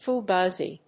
フガジ